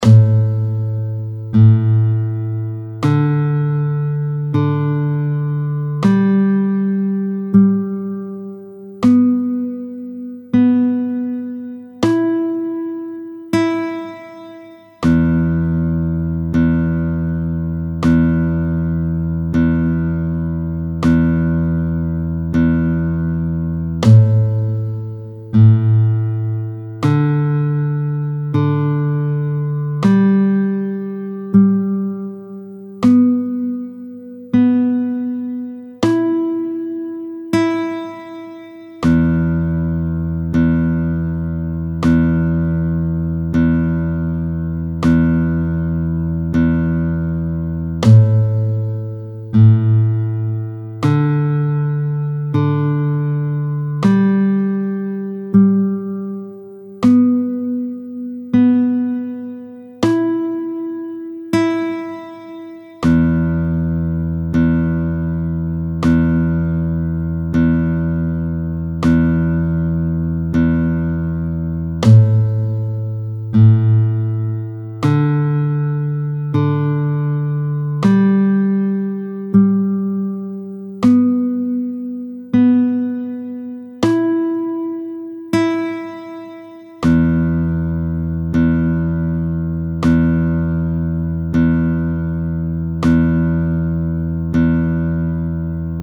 Accorder la guitare
02-01 Accorder la guitare, tempo 20 : LA (A), RE (D), SOL (G), SI (B), MI (E), MI (E) grave, MI grave…
Accordage-new.mp3